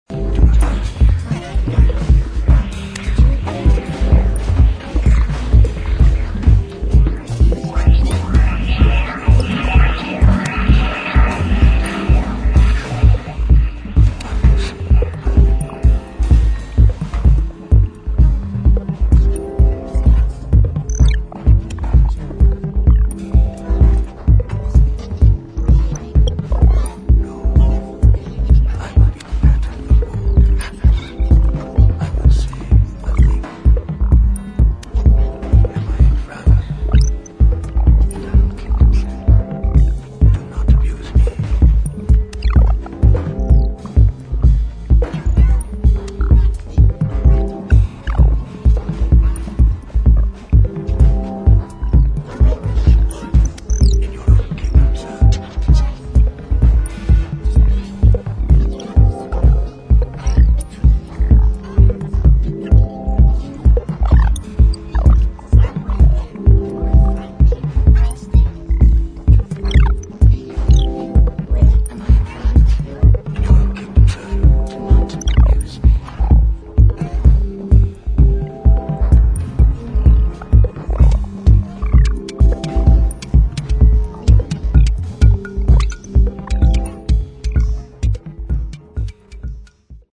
[ DEEP HOUSE / EXPERIMENTAL / TECHNO ]